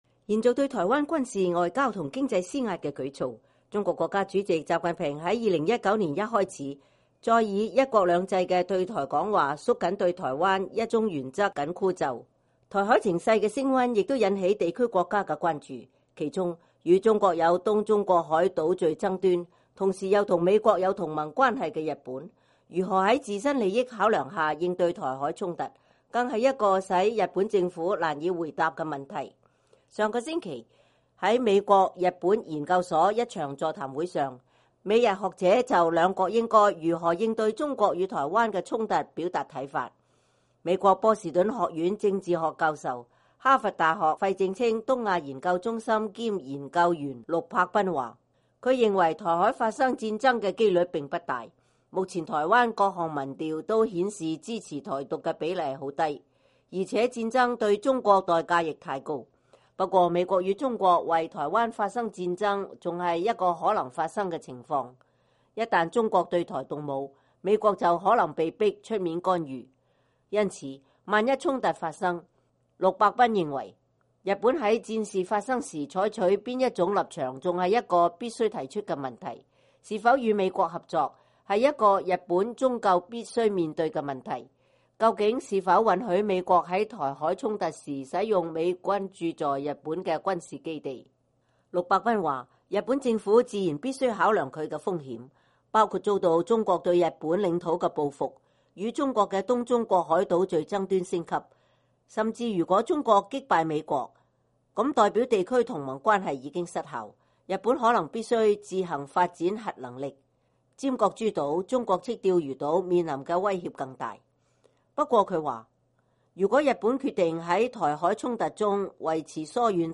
上星期，在美國-日本研究所(USJI)一場座談會上，美、日學者就兩國應該“如何應對中國與台灣的衝突”表達看法。